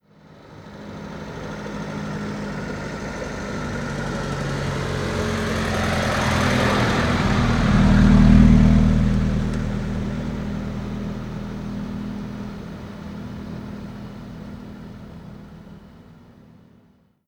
Moto marca BMW pasando despacio
motocicleta
Sonidos: Transportes